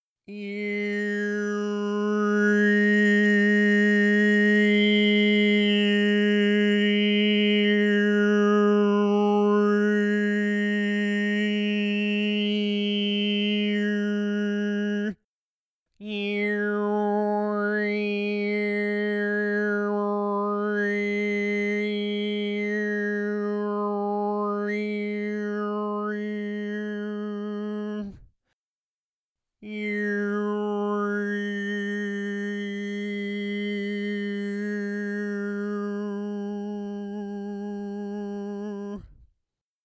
So I decided to still make sounds with my voice -- just not words or articulate sounds. What I'm about to show you is known as overtone singing, and it works by manipulating throat, sinus, and mouth shape to bring out partials of the harmonic overtone series.
(Just to clarify, you should be listening to the high, warbley, whistle-like tone about an octave above the tone I am singing, which sounds a bit like errrrrrrrrrrrr.)